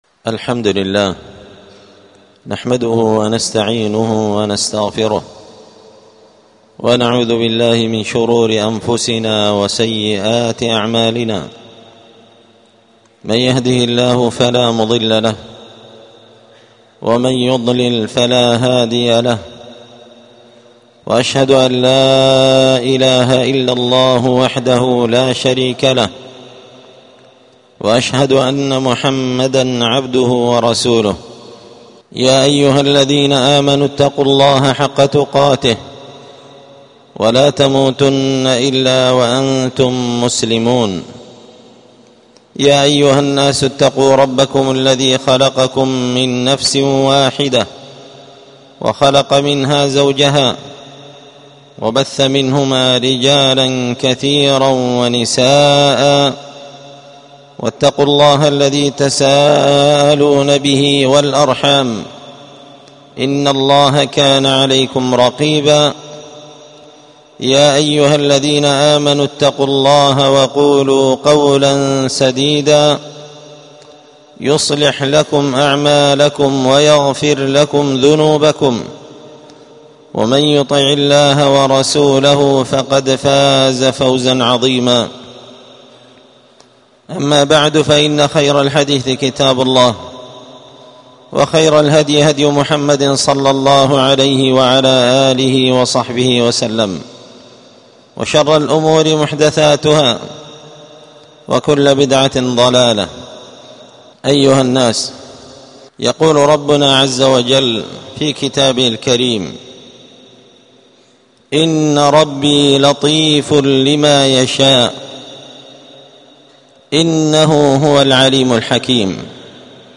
خطبة جمعة بعنوان: {إن ربي لطيف لما يشاء}
ألقيت هذه الخطبة بدار الحديث السلفية بمسجد الفرقان